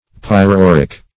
Search Result for " pyrouric" : The Collaborative International Dictionary of English v.0.48: Pyrouric \Pyr`o*["u]"ric\, a. [Pyro- + uric.]